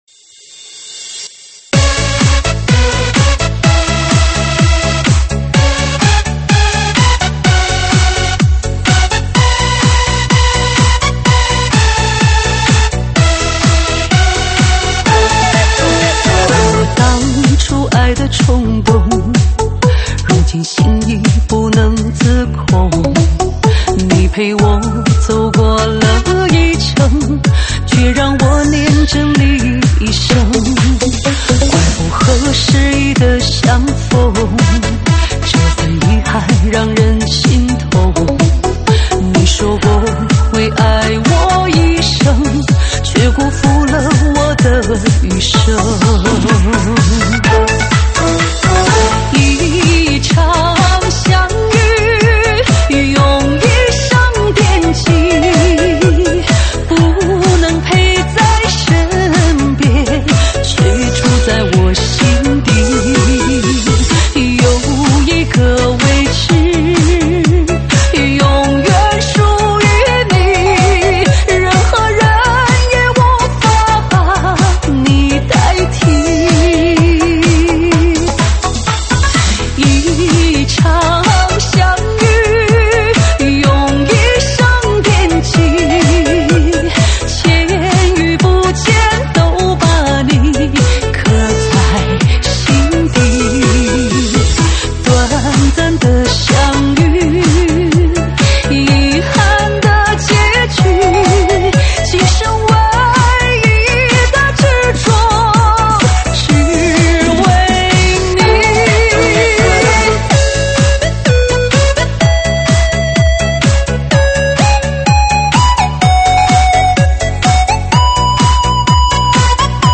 现场串烧
舞曲类别：现场串烧